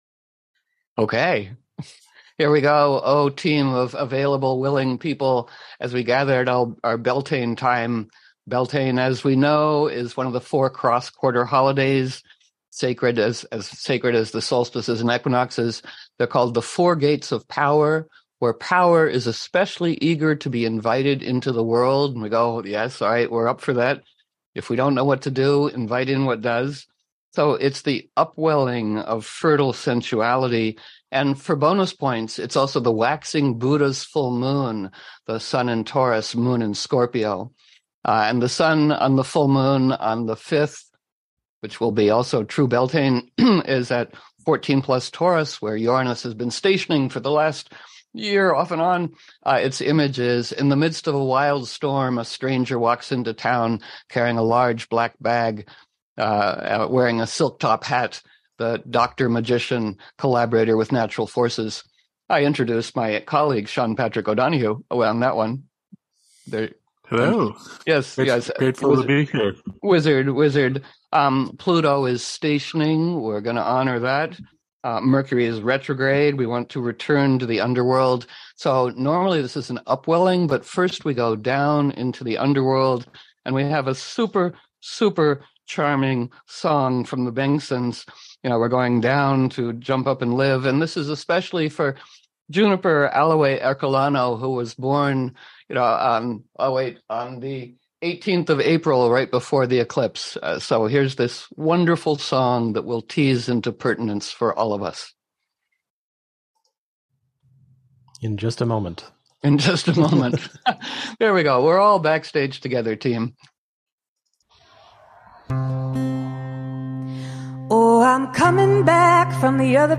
Beltaine virtual Gathering - Coyote Network News